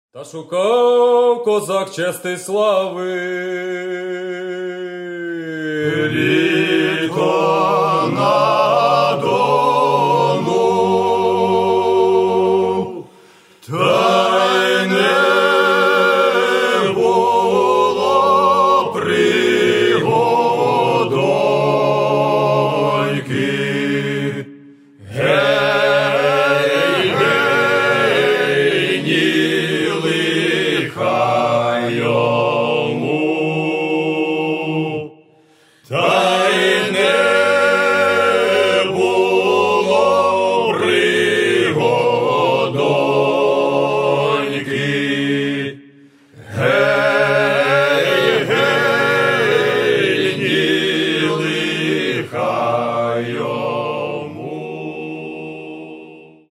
Каталог -> Народная -> Старинная музыка